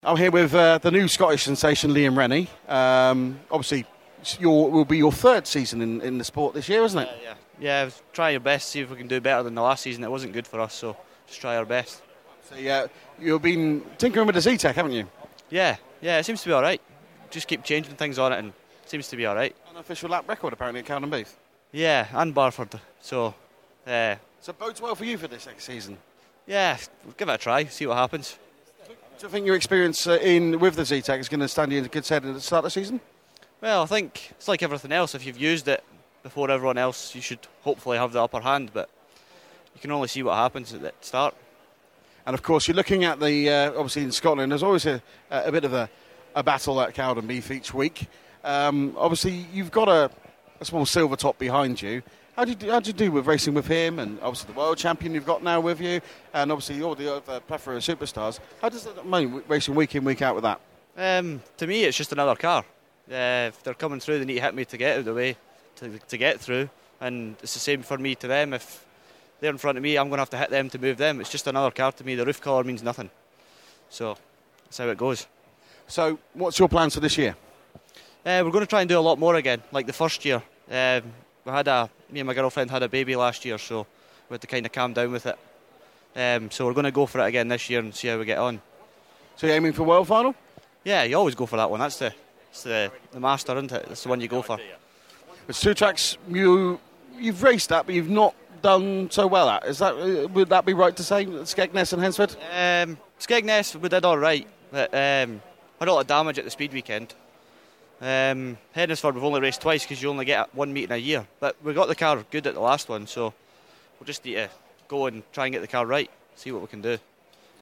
Autosport Show - Interview